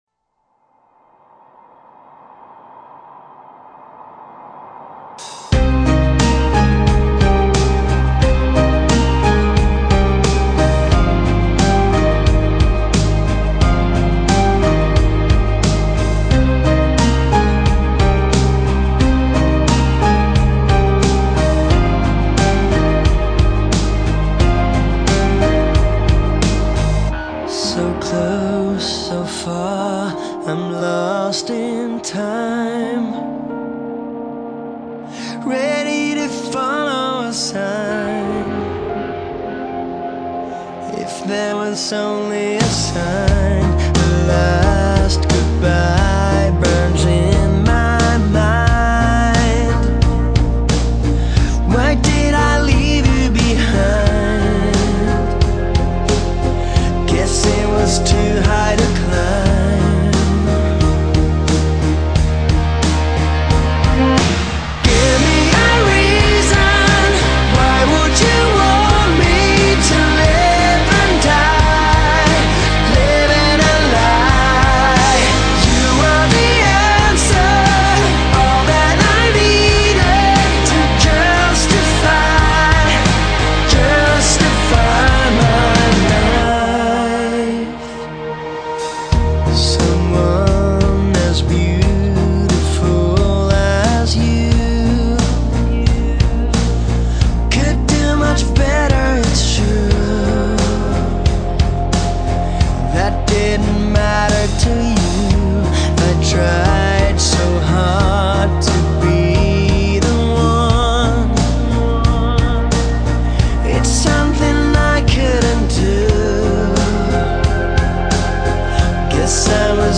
rock музыка